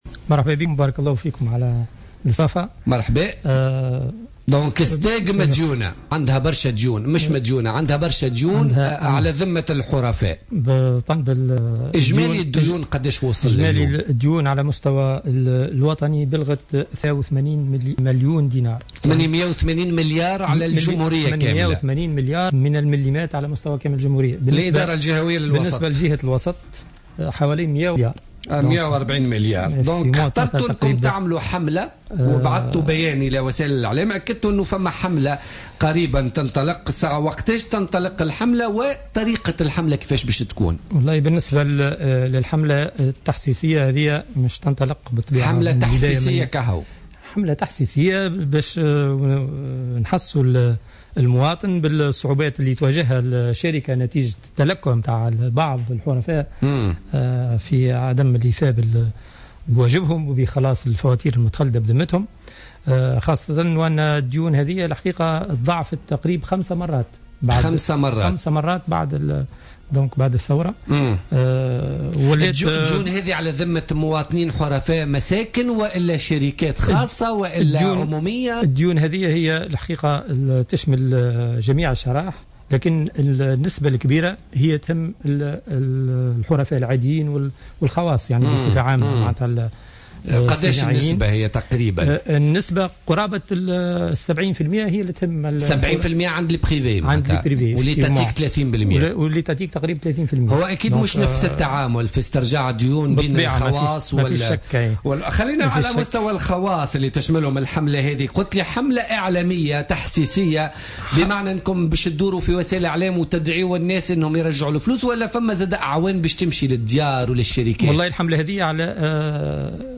في تصريح للجوهرة أف أم في برنامج بوليتكا